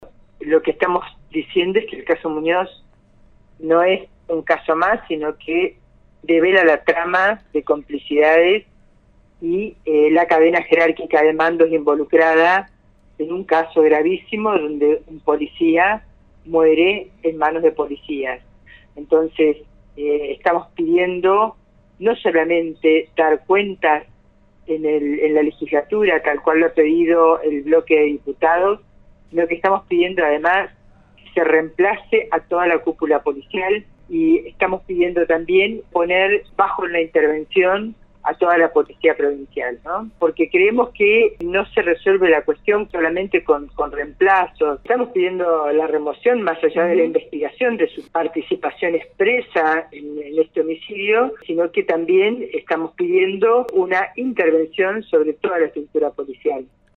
La Diputada Silvia Horne dijo que el testimonio del comisario revela la «trama de complicidades».